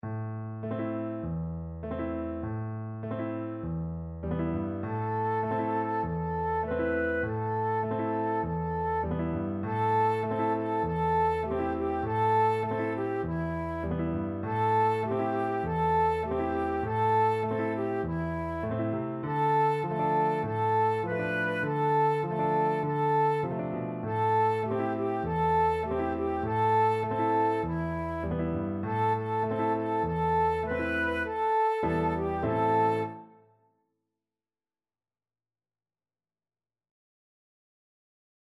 Flute version
4/4 (View more 4/4 Music)
Moderato
Traditional (View more Traditional Flute Music)